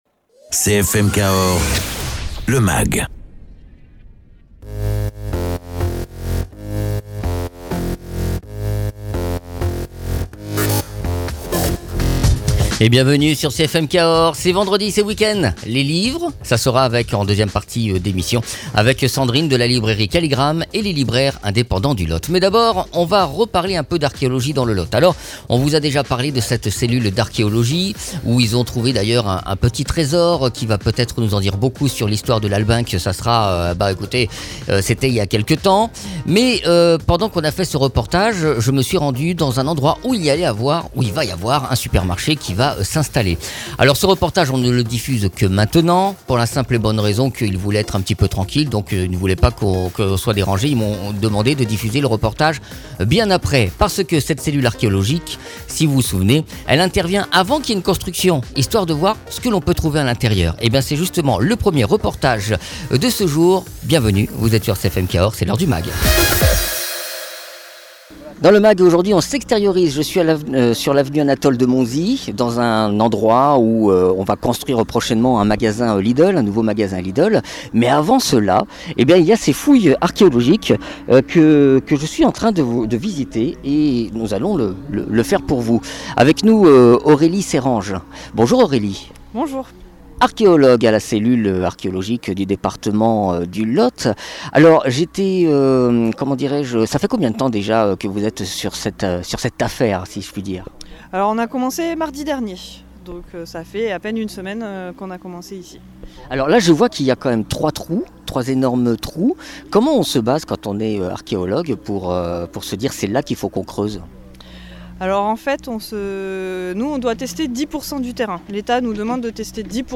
CFM Cahors à visité un chantier de fouilles archéologique à Cahors.